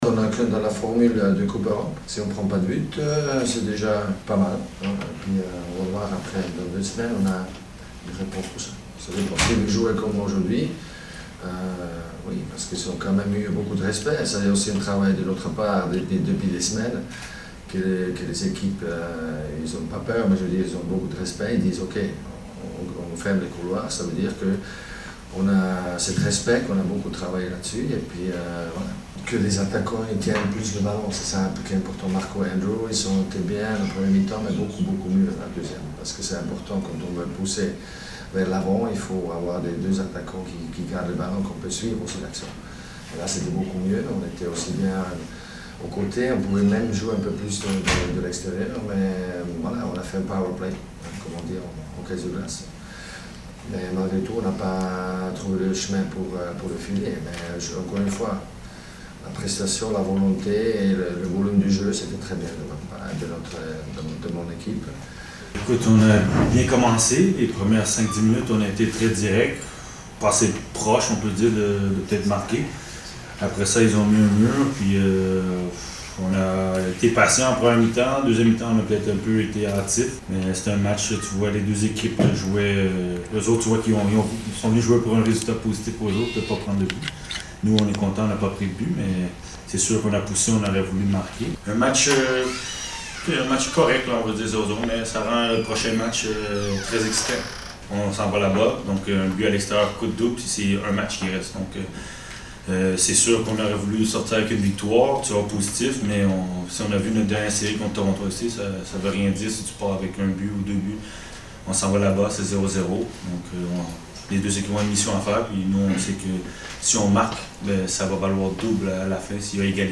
Conferenza stampa